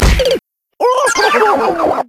omm_sound_event_death_mario_2.ogg